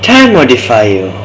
putimetravelvoice.wav